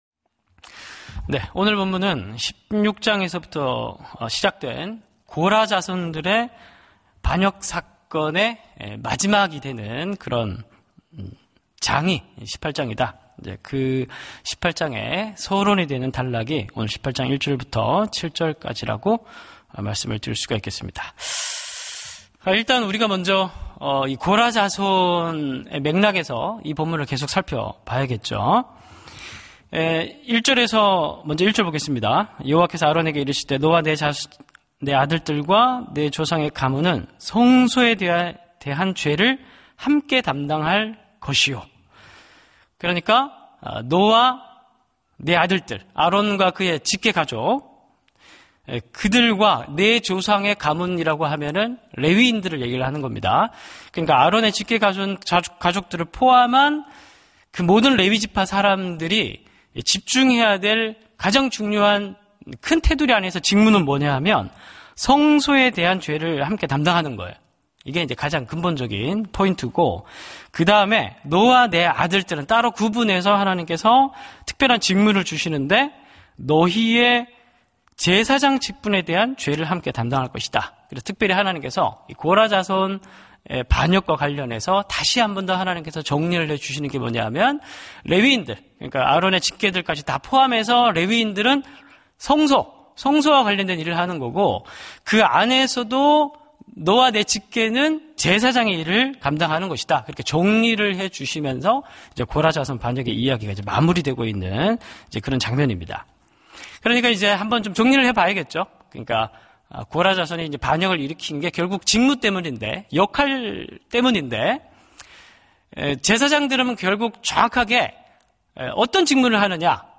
새벽예배